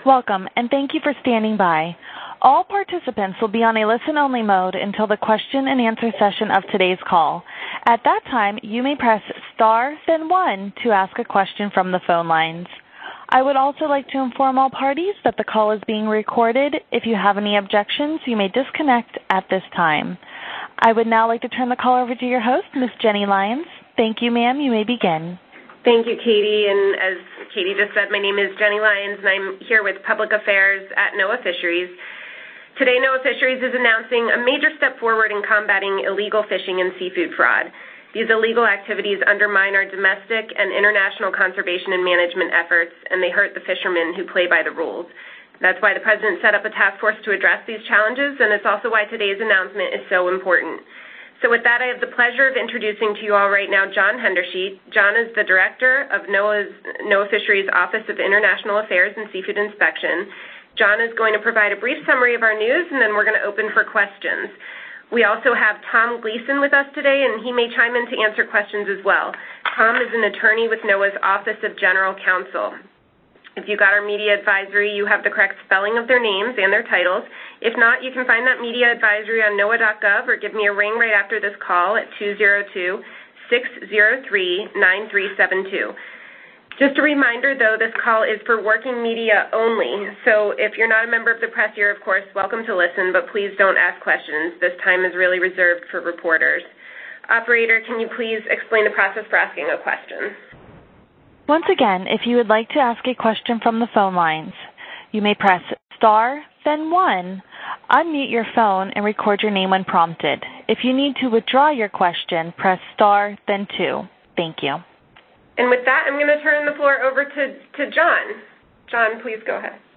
On Thursday, December 8, NOAA Fisheries will hold a media briefing to announce the creation of the Seafood Import Monitoring Program — a U.S. requirement which will raise the bar on seafood imports to further curb illegal fishing and fraud.